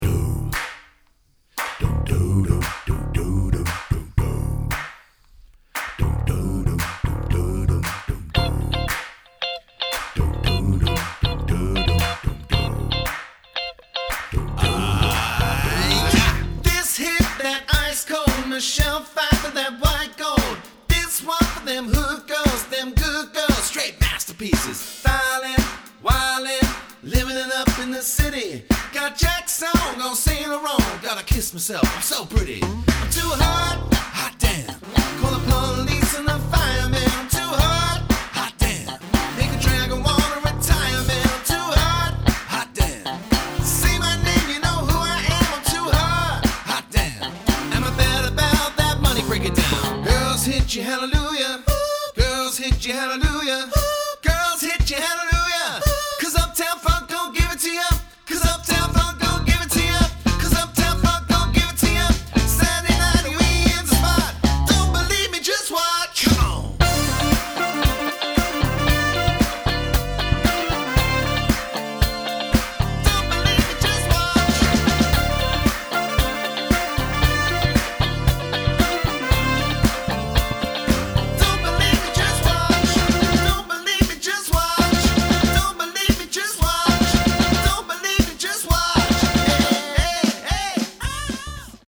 bursting with vibrance, fun and energy
rich, roaring voice
guitar
keyboard
bass
drums
• Highly experienced 4-7 piece function band